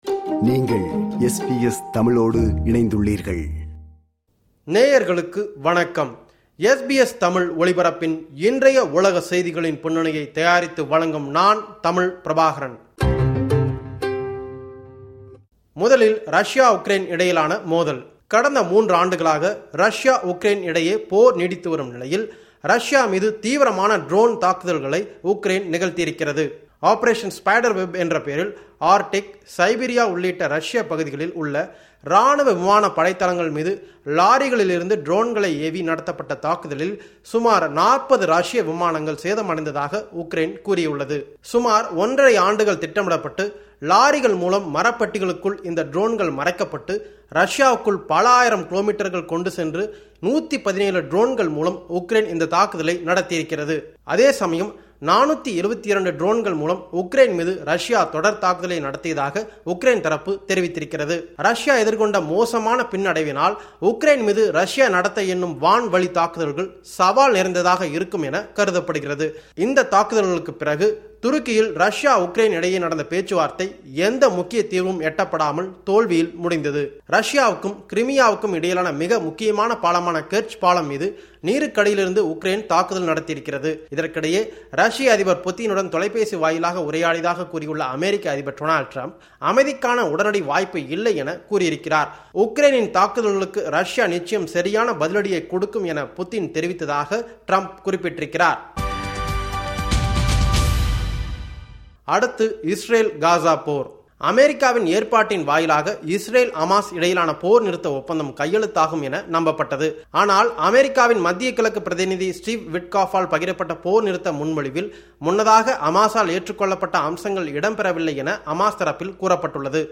இந்த வார உலக செய்திகளின் தொகுப்பு